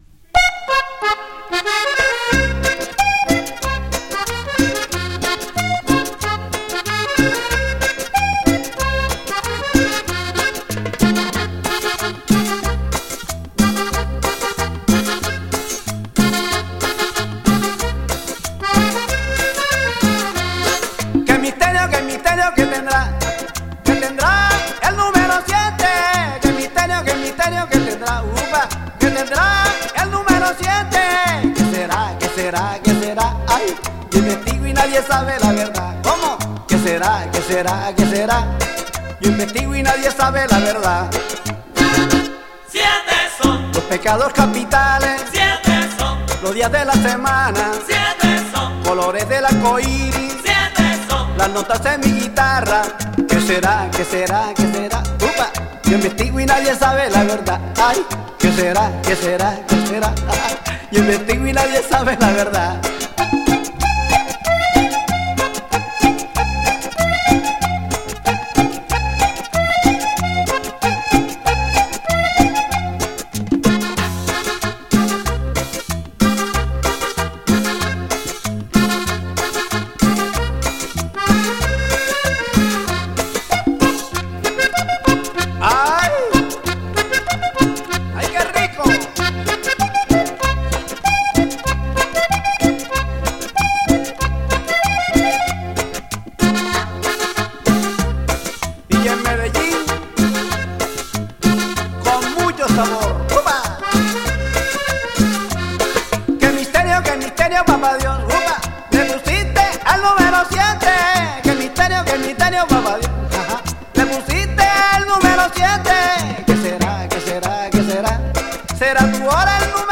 accordion heavy cumbia
These songs can really get the dance floor going.